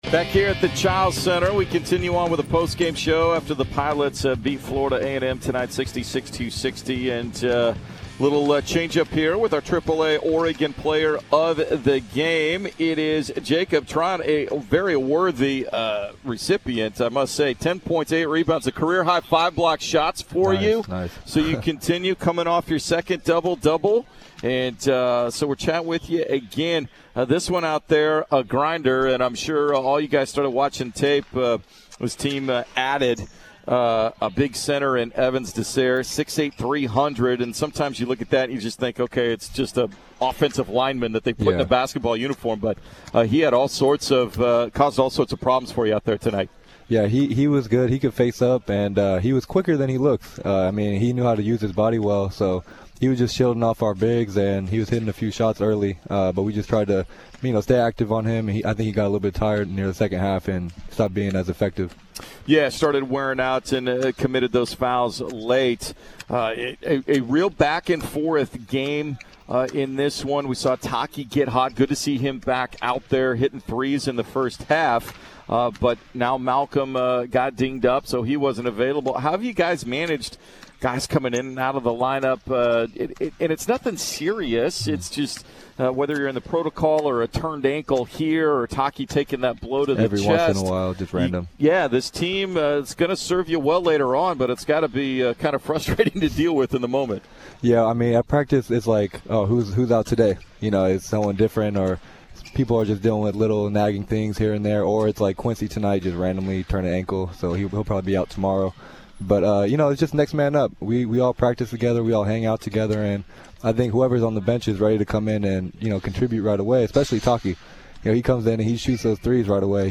Men's Hoops Post-Game Interviews vs. Florida A&M